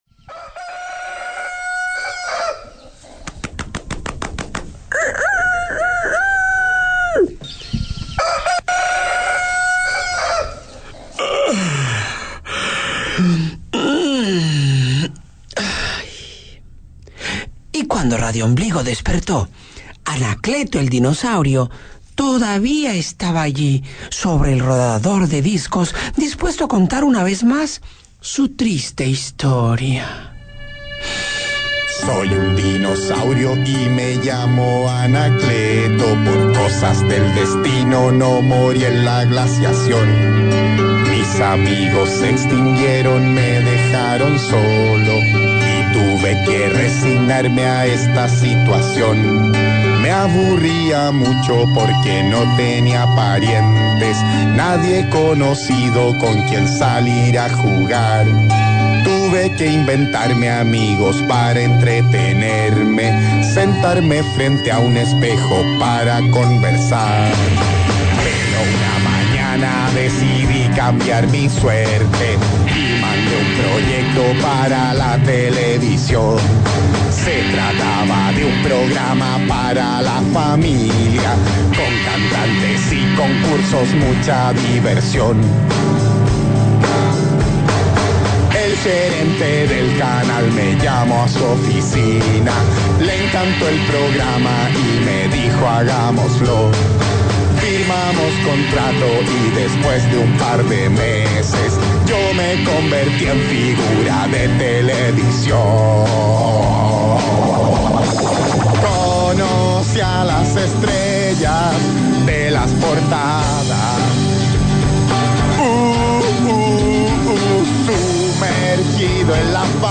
Luego, como que volvió a llegar y lanzó canciones como si las regalaran.